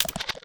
creaking_step3.ogg